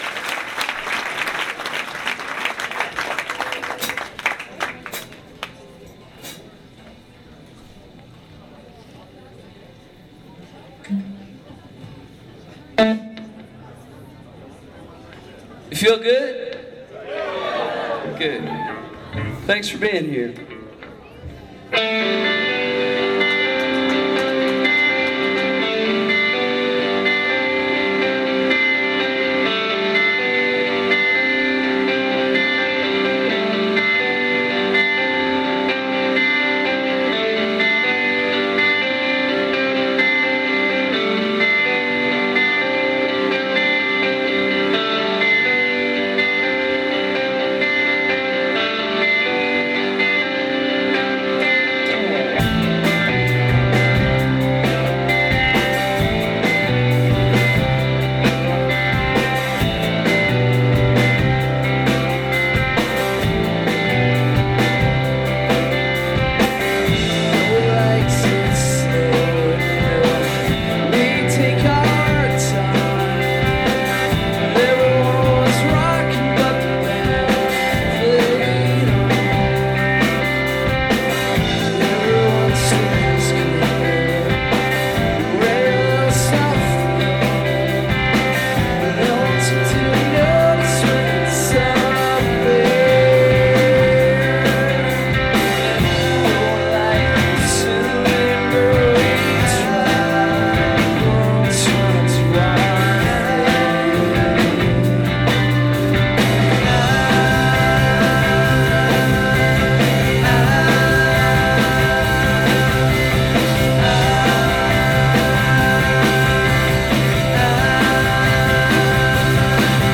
knitting factory hollywood June 16 2001